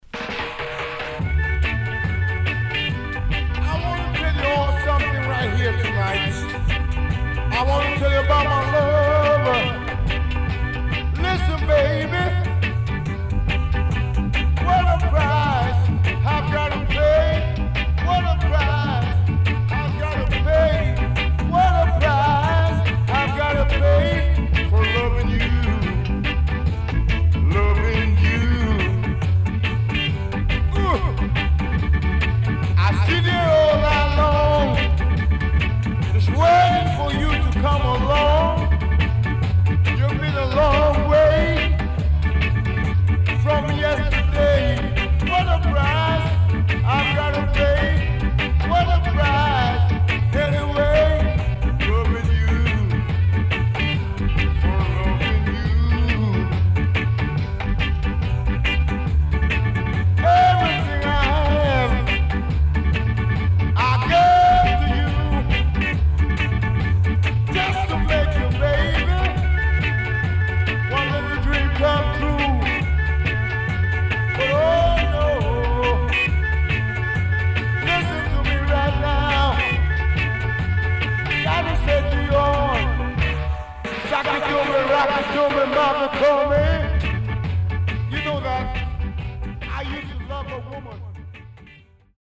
HOME > REISSUE [REGGAE / ROOTS]
Great Early Reggae Vocal